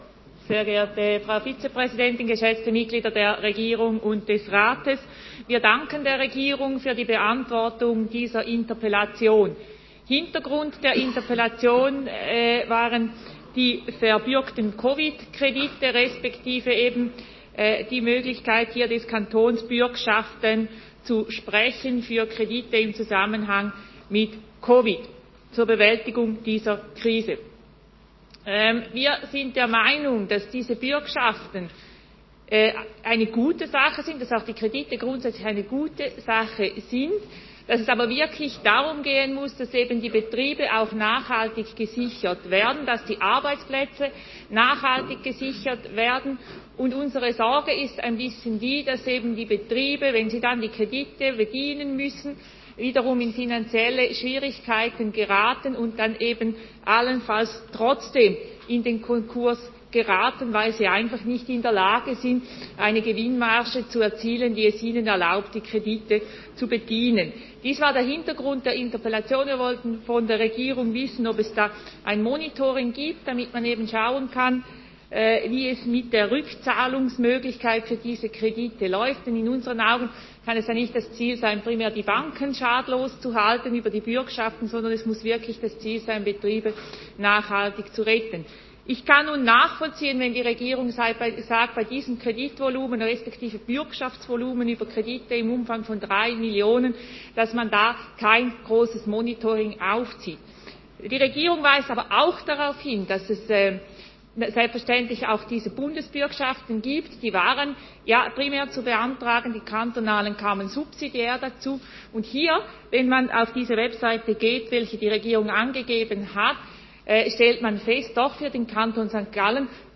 1.12.2020Wortmeldung
Session des Kantonsrates vom 30. November bis 2. Dezember 2020